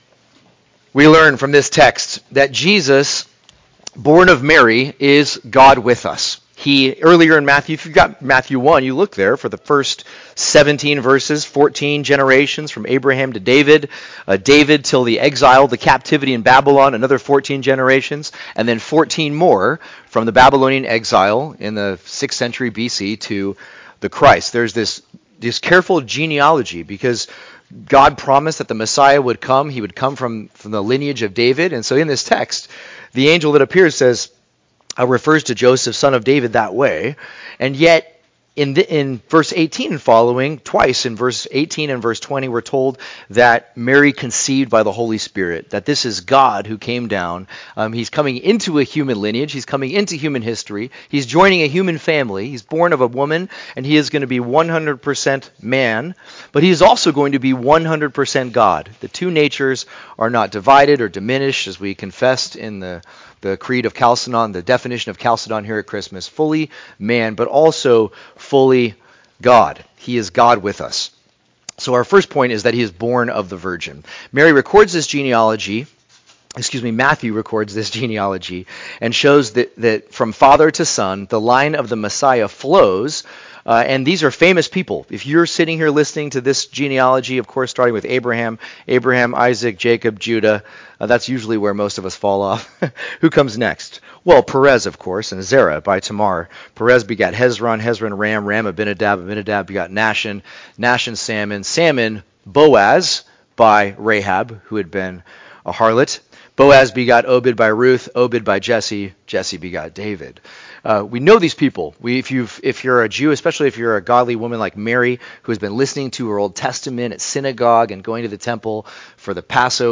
Christmas Homily